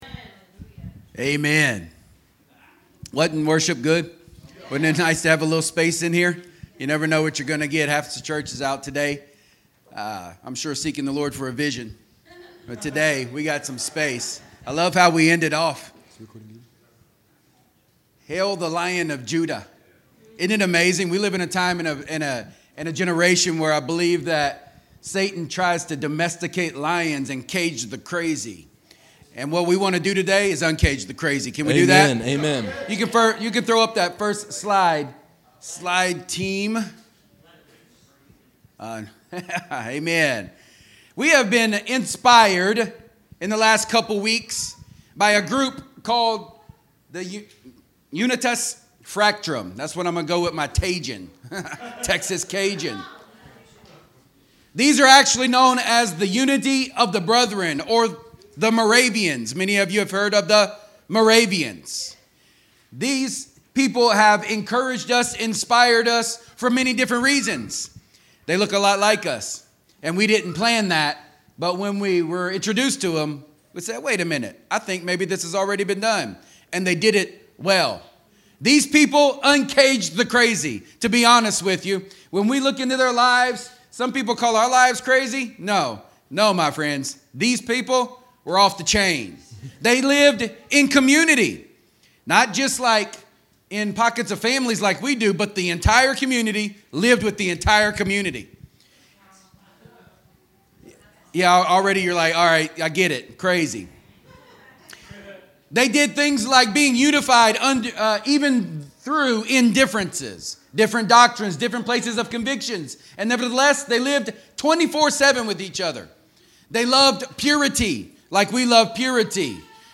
In this powerful sermon, we dive into the bold faith of the Moravian Church and explore the writings of Paul—especially 2 Corinthians 5—to reveal why being “out of your mind” for God is not only acceptable but essential. Like the Moravian brotherhood, we are those who follow the Lamb who has conquered.